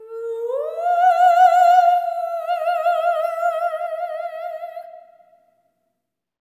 ETHEREAL01-R.wav